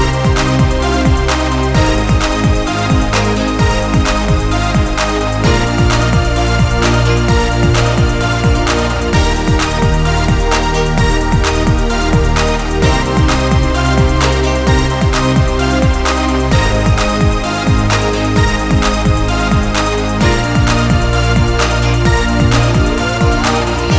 no Backing Vocals or Rapper Pop (2010s) 3:36 Buy £1.50